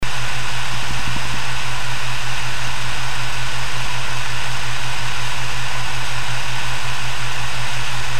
Something that I couldn't bear is the fast spinning 7200RPM fan. It is way too loud.
The test was done outside the casing with unidirectional microphone pointing directly to the fan.